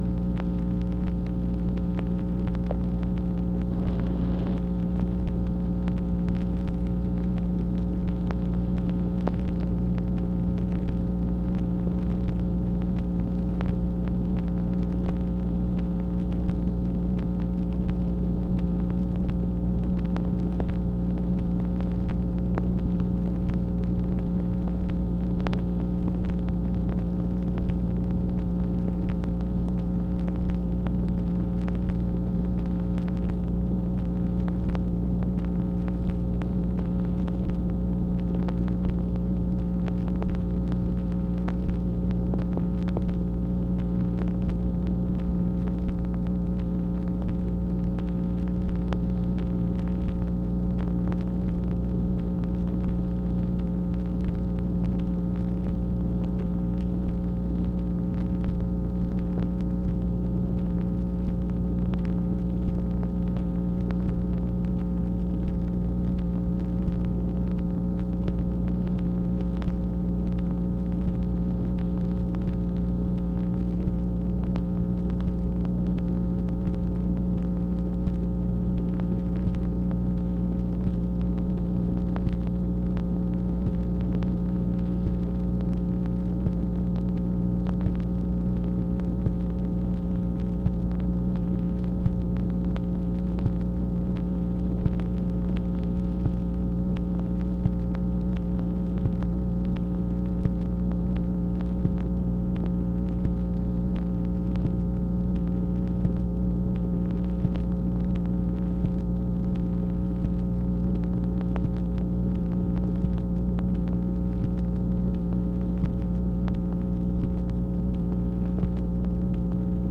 MACHINE NOISE, September 19, 1966
Secret White House Tapes | Lyndon B. Johnson Presidency